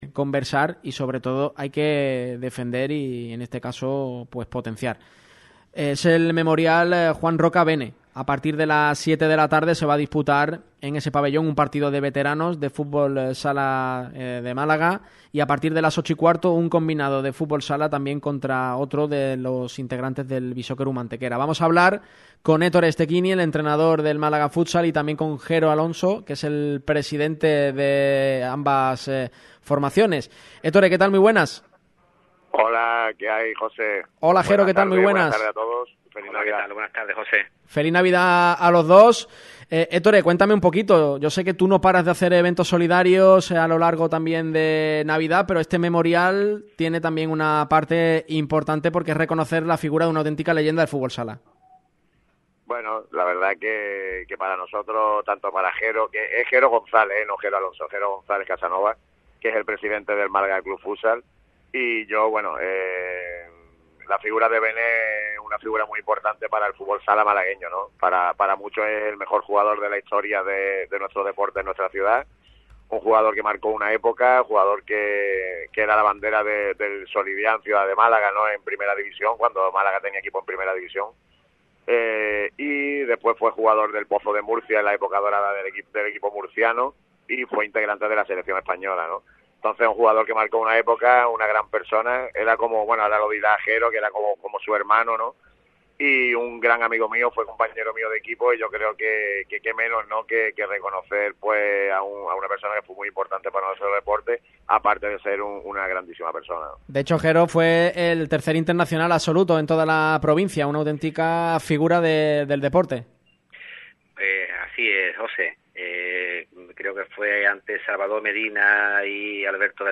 pasaron por el micrófono rojo de Radio MARCA Málaga